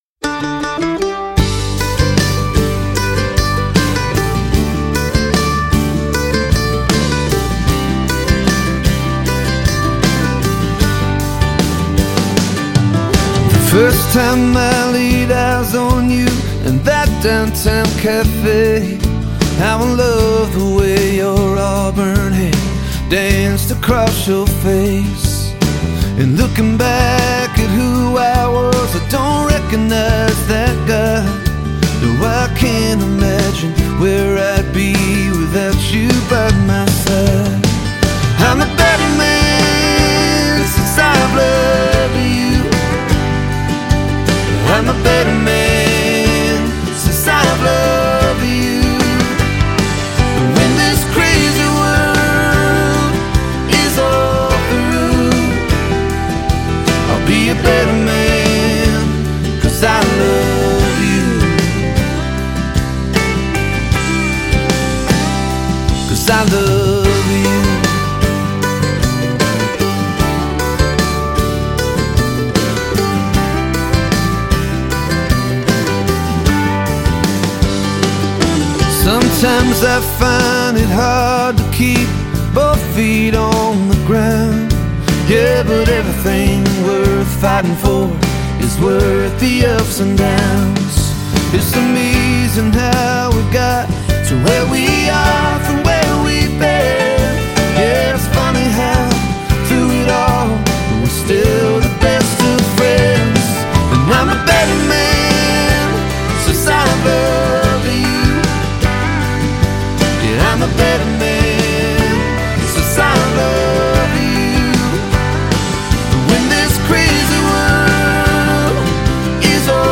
American Country music trio